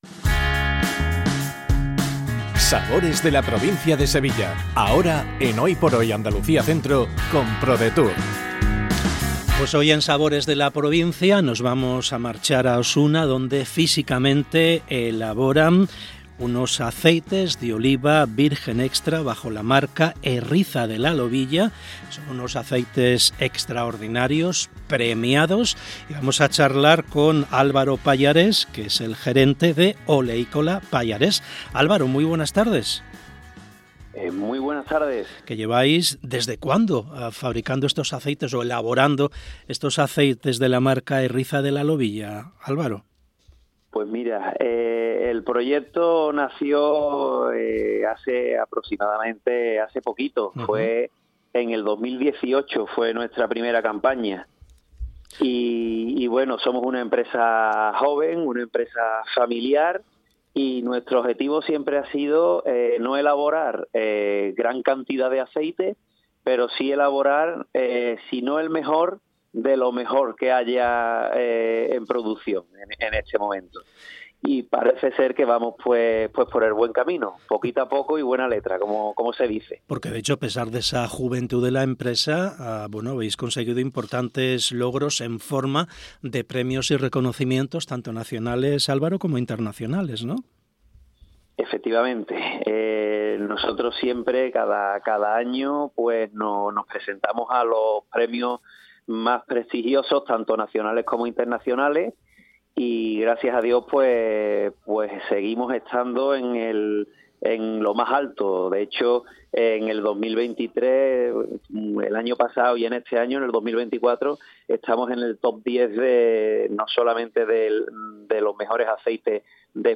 ENTREVISTA | AOVE HERRIZA LA LOBILLA